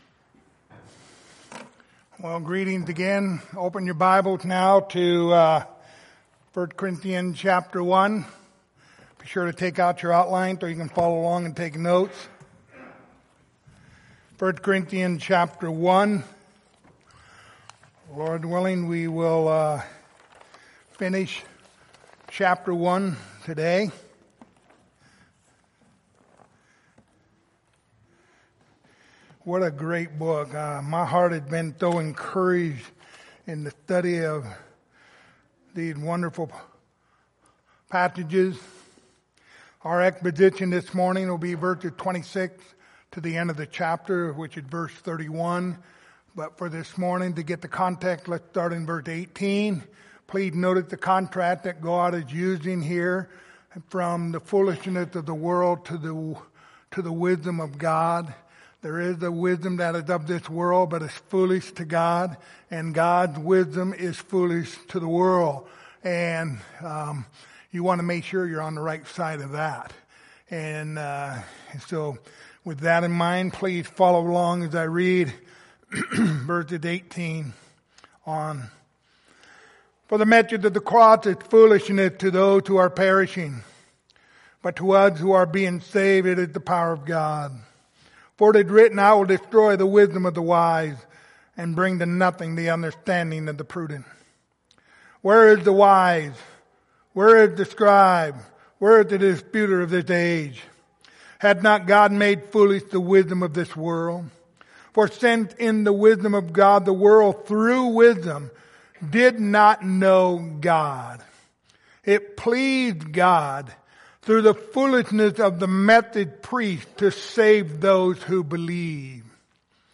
Passage: 1 Corinthians 1:26-31 Service Type: Sunday Morning